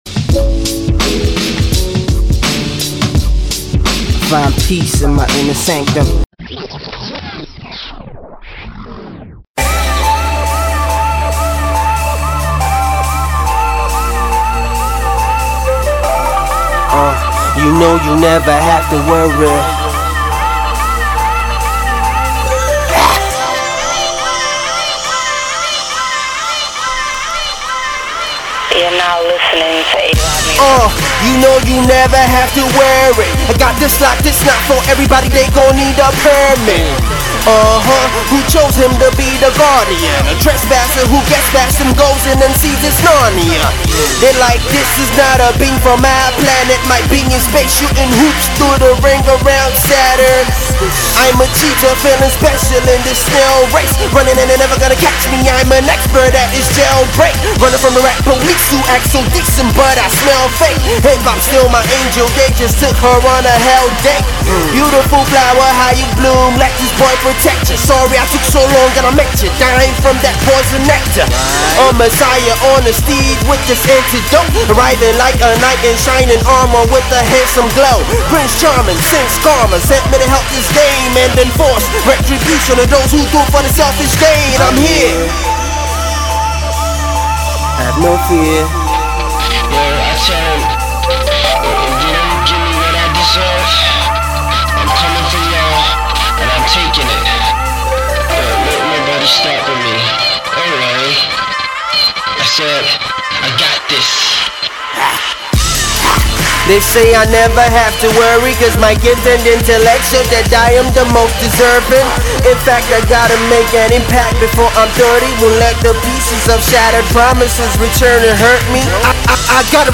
ace Nigerian rapper